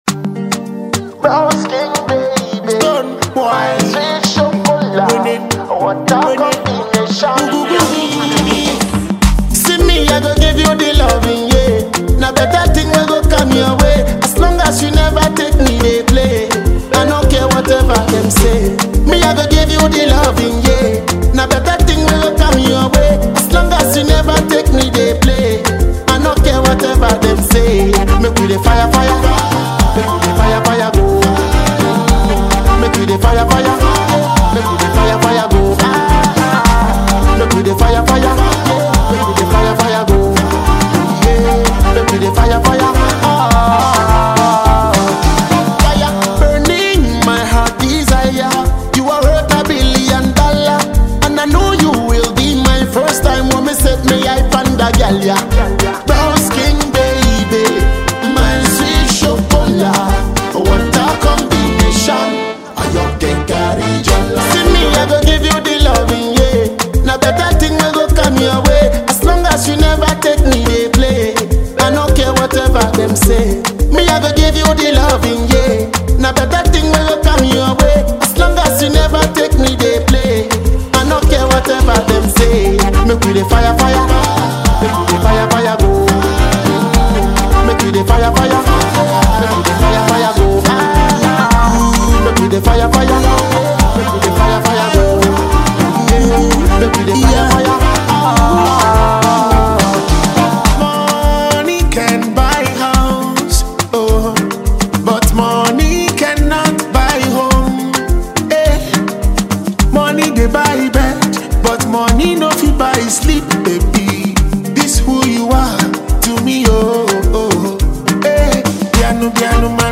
Dancehall music prodigy and a Ghanaian artiste
Showring an inspirational banger